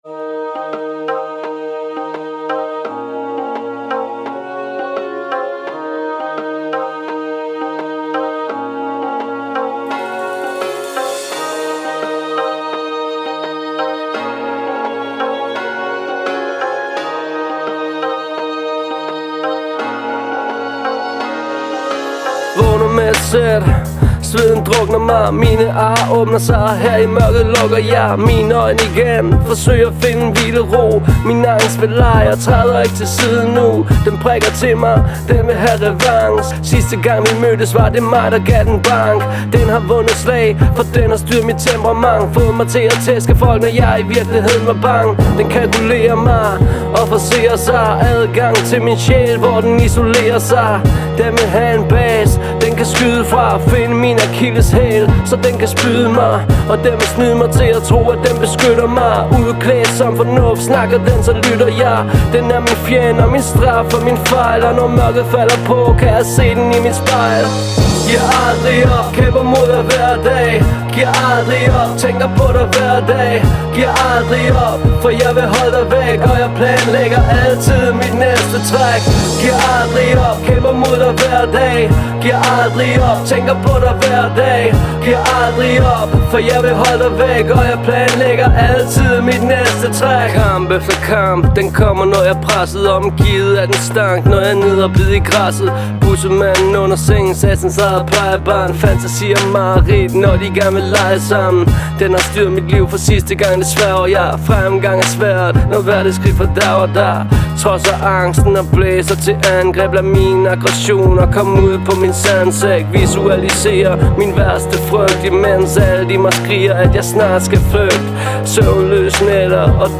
Genren er hiphop.